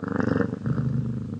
purr3.ogg